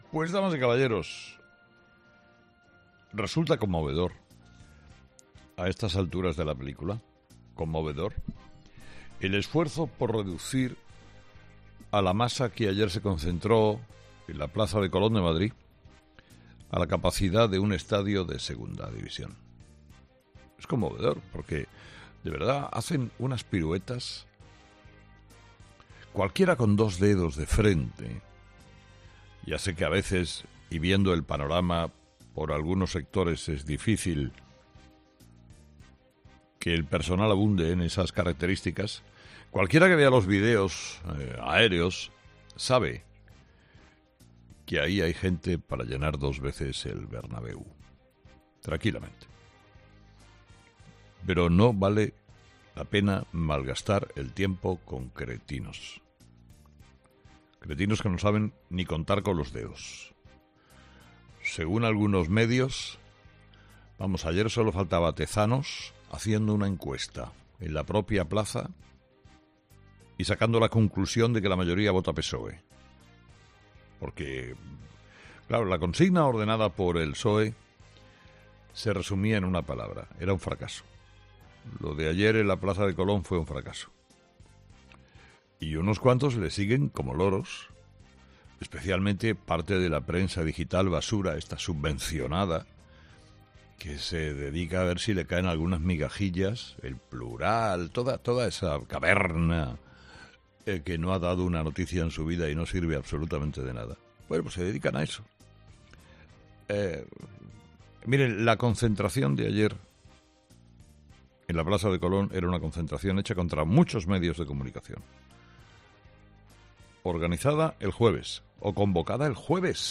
A este respecto se ha referido Herrera en su monólogo de este lunes: "Resulta conmovedor a estas alturas de la película el esfuerzo por reducir a la masa que ayer se concentró en la plaza de Colón de Madrid a la capacidad de un estadio de un equipo de segunda división.